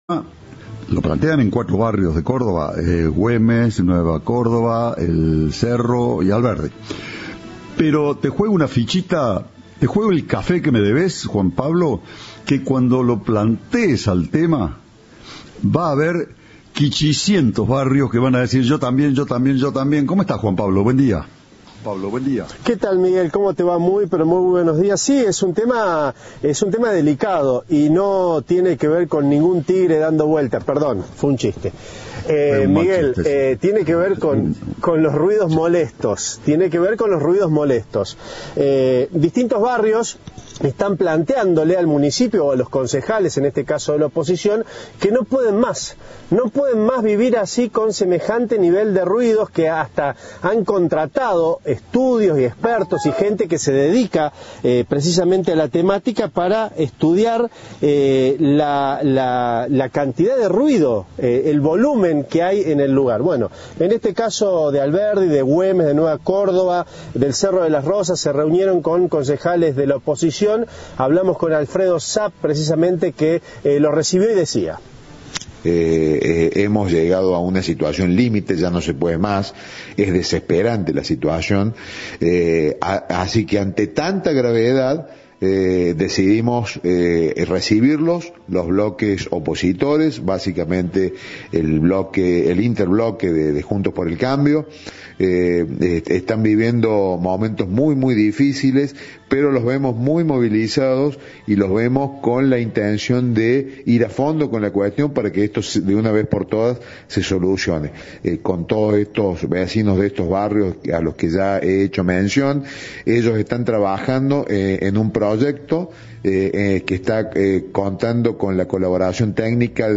En diálogo con Cadena 3, el concejal Alfredo Saap, dijo que "la situación es límite y ya no se puede más".